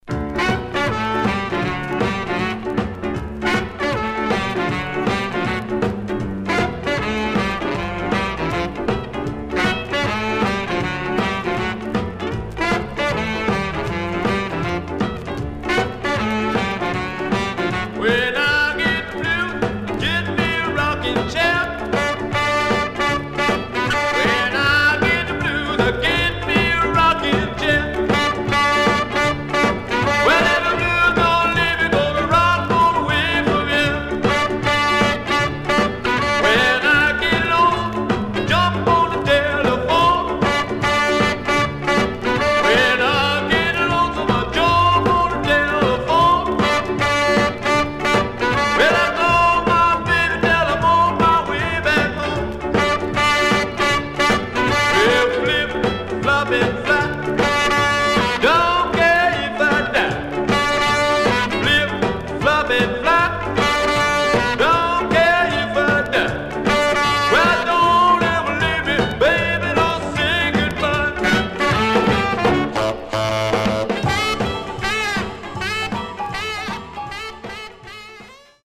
Some surface noise/wear Stereo/mono Mono
Rockabilly